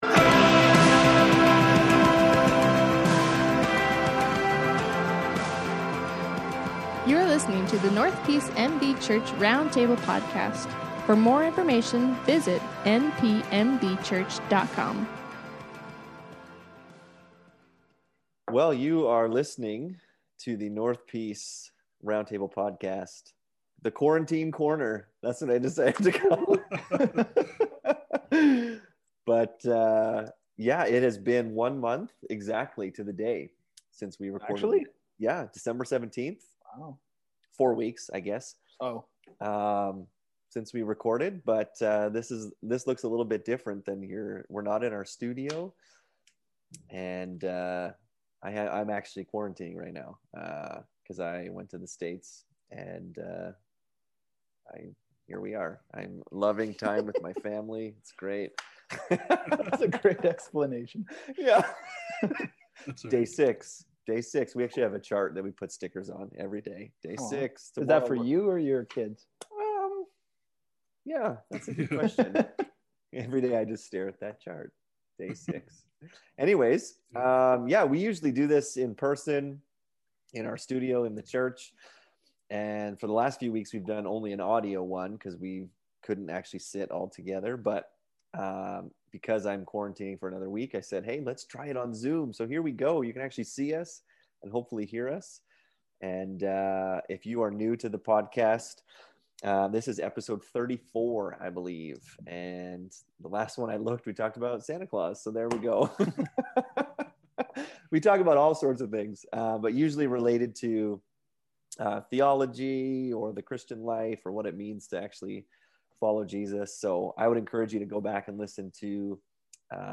In this episode of the podcast, the guys talk about faith.